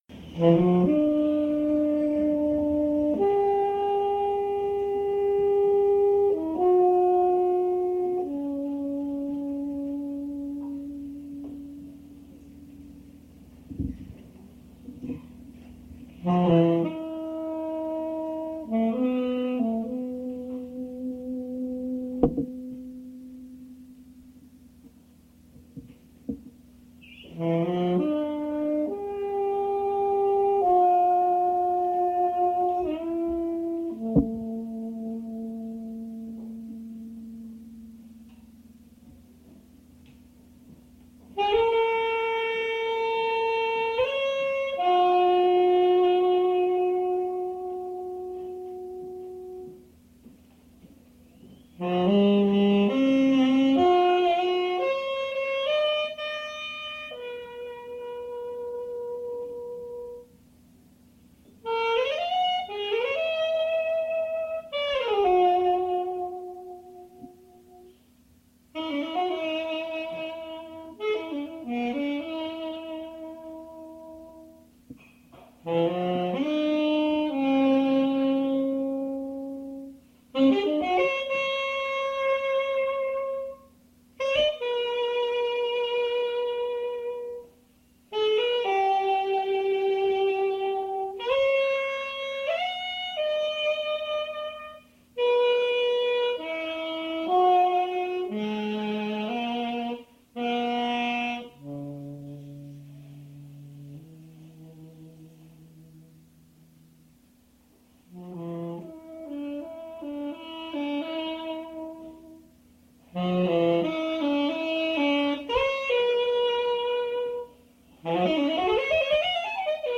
Poetry reading and music
at Duff's Restaurant
mp3 edited access file was created from unedited access file which was sourced from preservation WAV file that was generated from original audio cassette.
1964-2014 Note All pieces are accompanied by music.